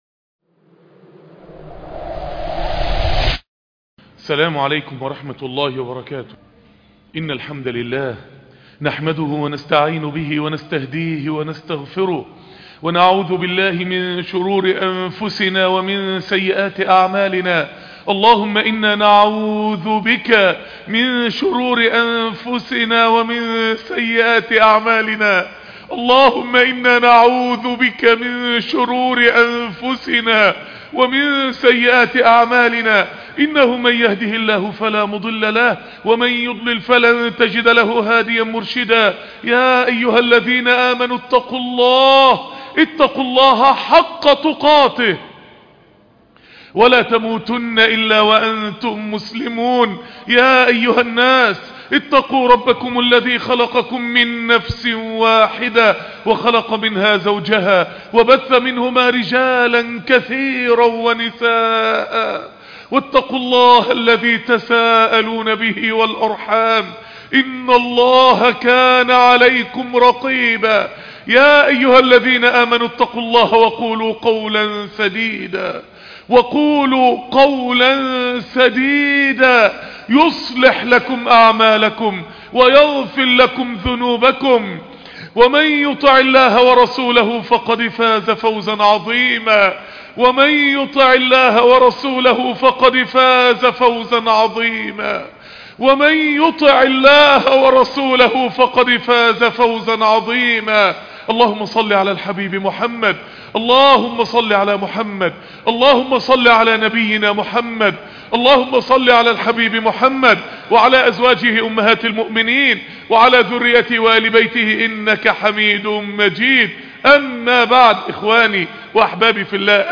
عاجز عن الشكر - -- .. خطبة رااااااائعة جداااااااا ..